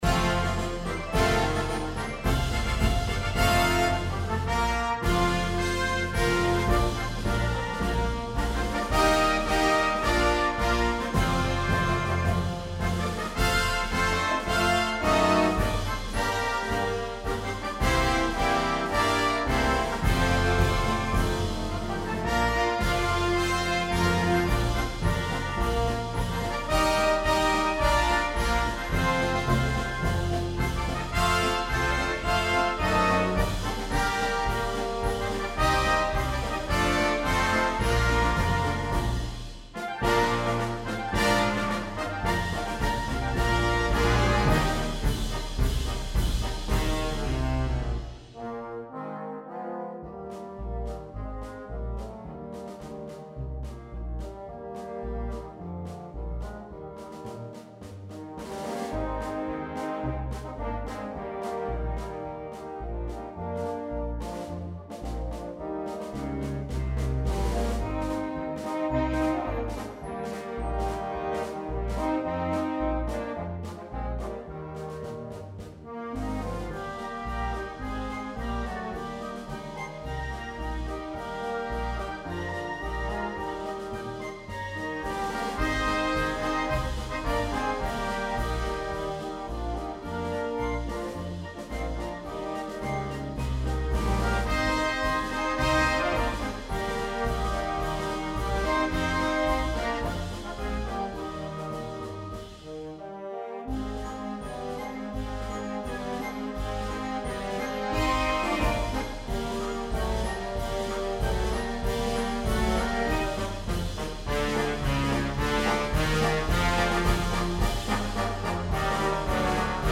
Besetzung: Concert Band